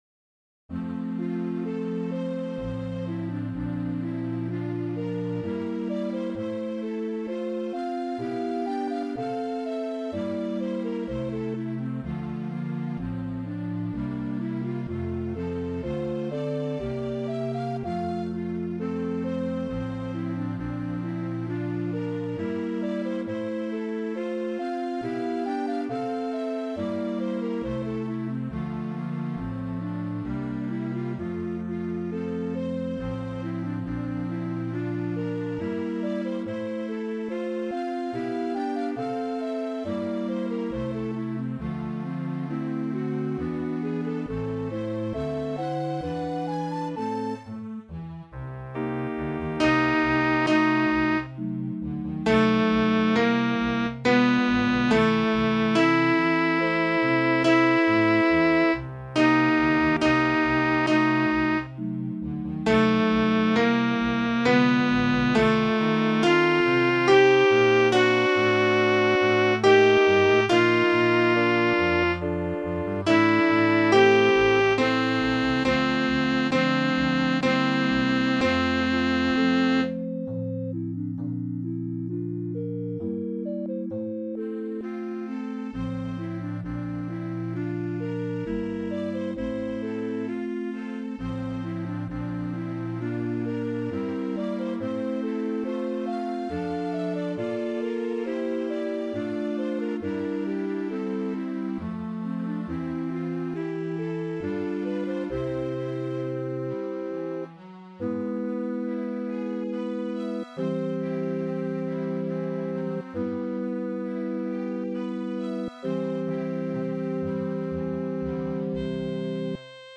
Faure tracks – alto